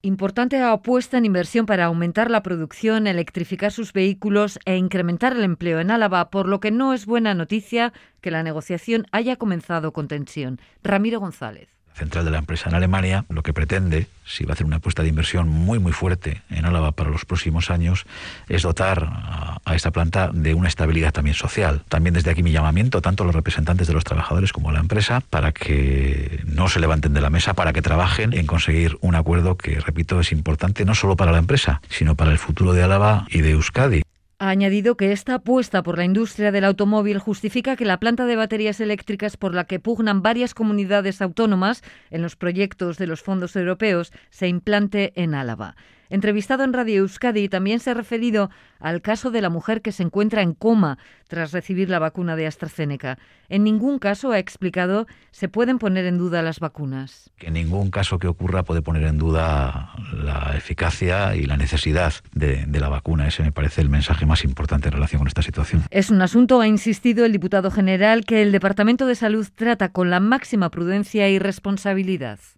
Audio: Entrevistado en Radio Euskadi, el diputado general de Araba, Ramiro González, ha pedido a la dirección y a los sindicatos no se levanten de la mesa